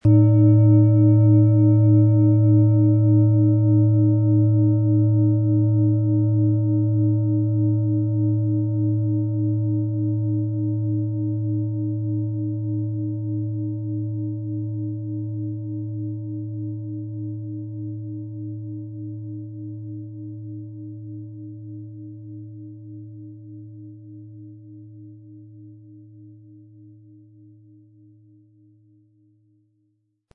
Von Hand getriebene Klangschale mit dem Planetenklang Venus aus einer kleinen traditionellen Manufaktur.
• Höchster Ton: Eros
Im Audio-Player - Jetzt reinhören hören Sie genau den Original-Klang der angebotenen Schale. Wir haben versucht den Ton so authentisch wie machbar aufzunehmen, damit Sie gut wahrnehmen können, wie die Klangschale klingen wird.
PlanetentonVenus & Eros (Höchster Ton)
MaterialBronze